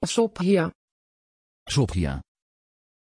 Prononciation de Sophia
pronunciation-sophia-nl.mp3